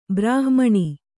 ♪ brāhmaṇi